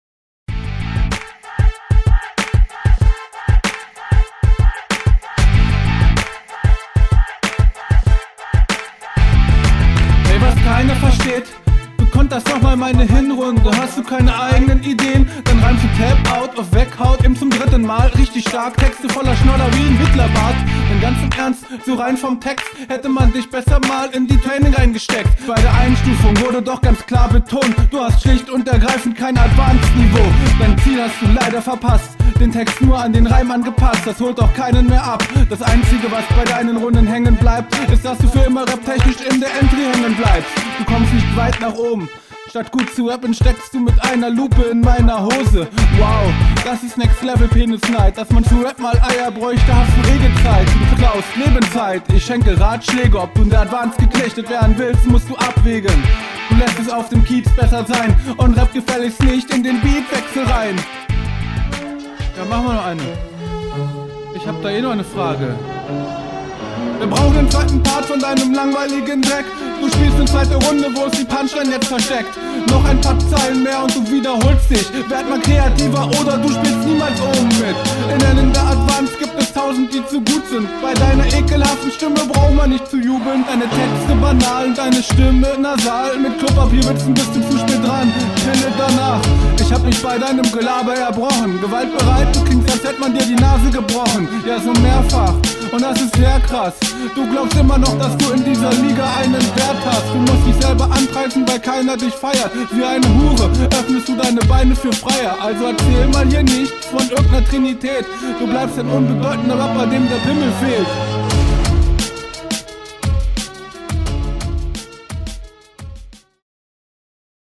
Ufff, alter du kommst auch ziemlich nice auf dem beat und bringst harte punches.